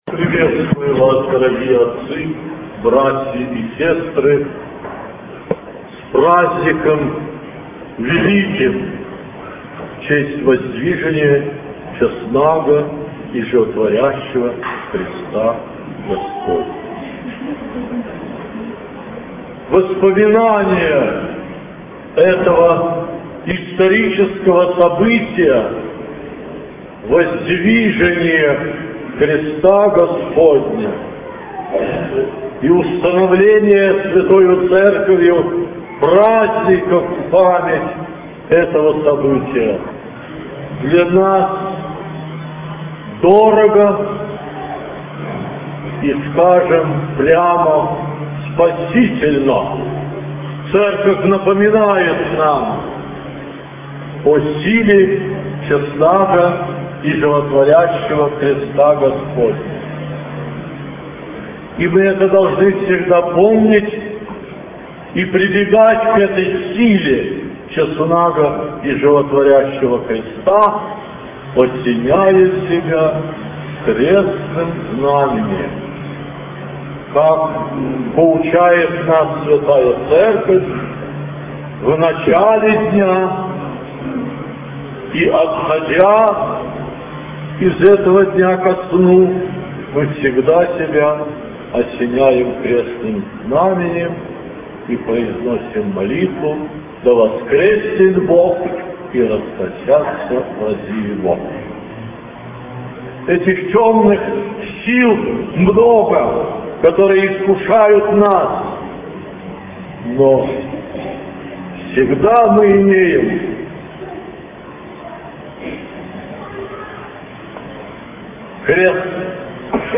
По богослужении Владыка проповедовал.
Проповедь Высокопреосвященнейшего Митрополита Филарета в праздник Воздвижения Честного и Животворящего Креста Господня, Свято-Духов кафедральный собор г. Минск.